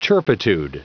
Prononciation du mot turpitude en anglais (fichier audio)
Prononciation du mot : turpitude